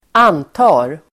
Uttal: [²'an:ta:r]